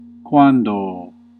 Ääntäminen
Ääntäminen France (Avignon): IPA: [kãŋ] Tuntematon aksentti: IPA: /kɑ̃/ IPA: /kɑ̃.t‿/ Haettu sana löytyi näillä lähdekielillä: ranska Käännös Ääninäyte 1. quandō 2. quando 3. cum Suku: m .